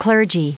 Prononciation du mot clergy en anglais (fichier audio)